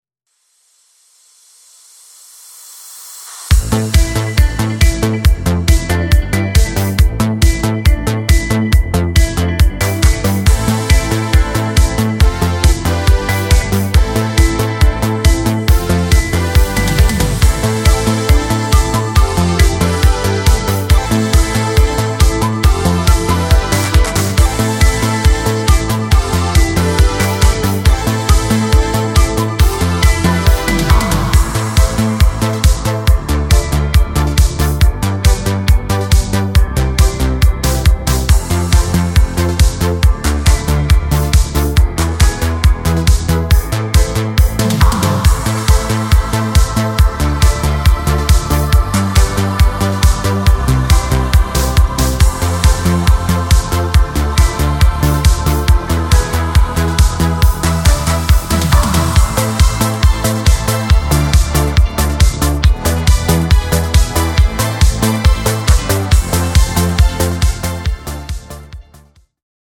nastrojowa piosenka
Disco Polo